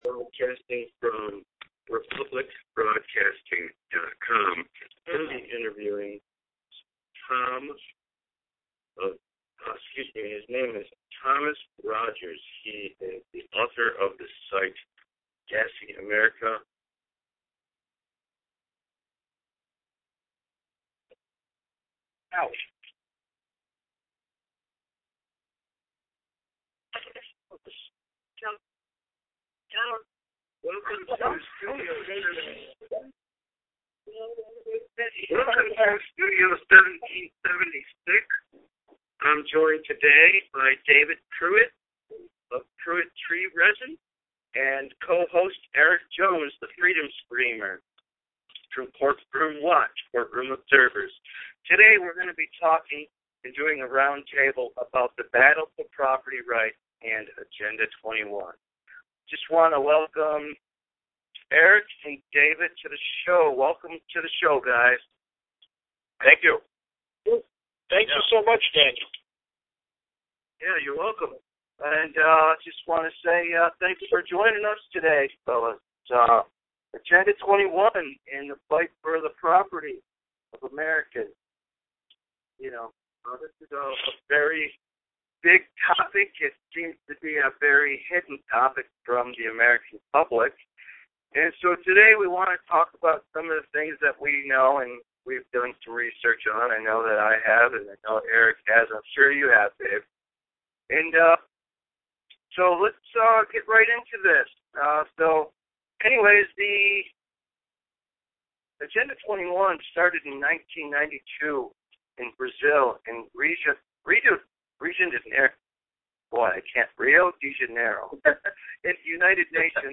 Studio 1776 Roundtable talks about the Battle for Property Rights and Agenda 21